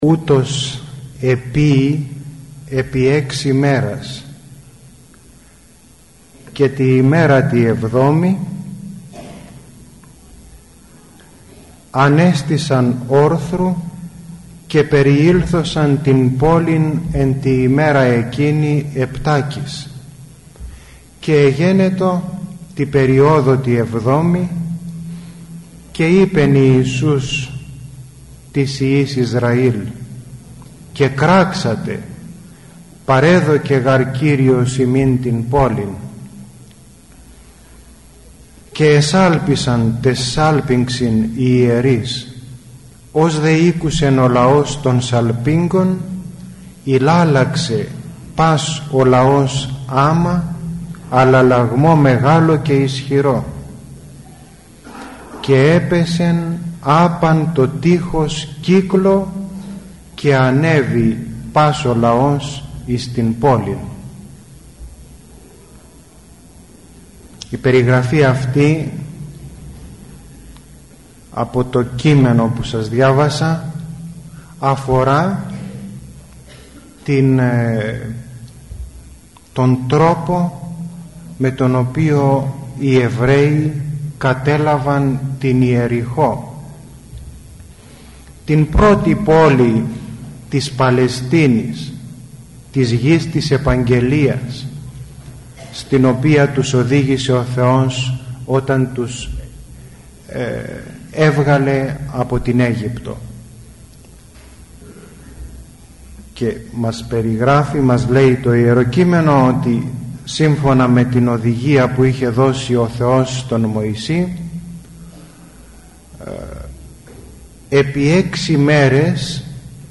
Τί είναι προσευχή; – ηχογραφημένη ομιλία
Η ομιλία αυτή “δόθηκε” στην αίθουσα της Χριστιανικής ενώσεως Αγρινίου.